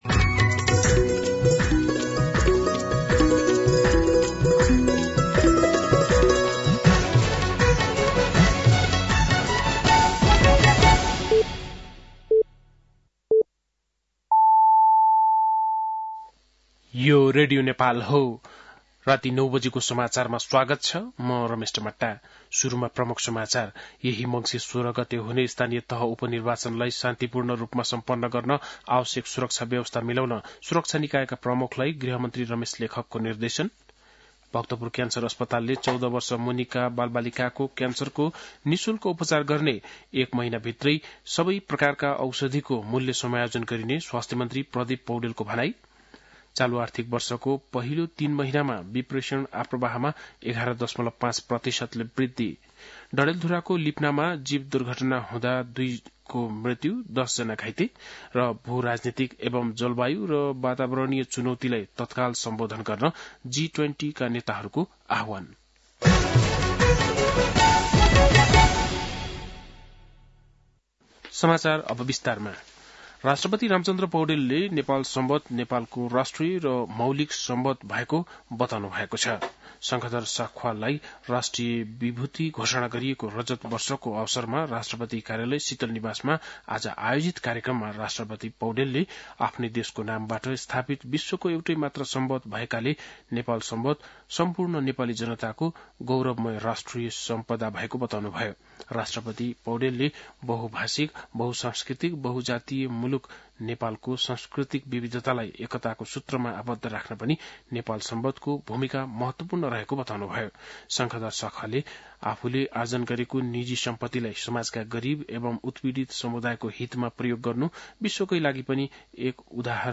बेलुकी ९ बजेको नेपाली समाचार : ५ मंसिर , २०८१
9-PM-Nepali-News-8-4.mp3